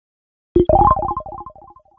Category: HTC Ringtones